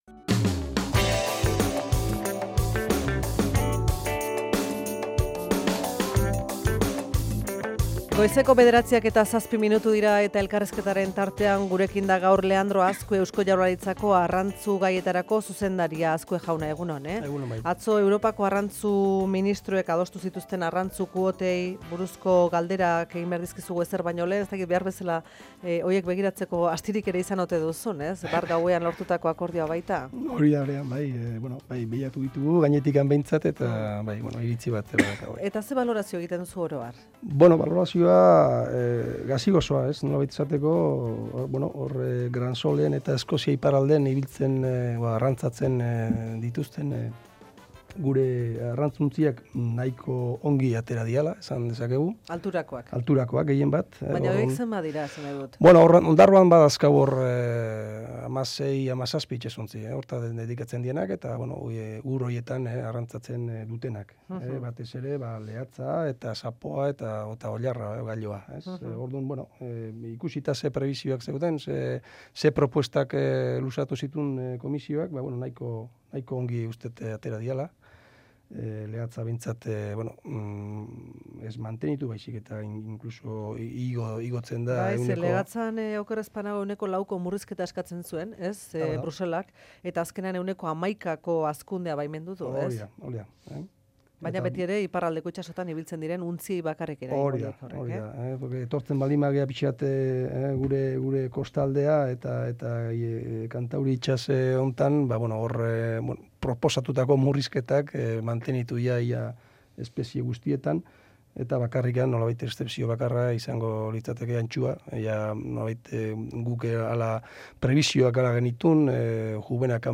Leandro Azkue, jaurlaritzako arrantza zuzendaria, Euskadi Irratia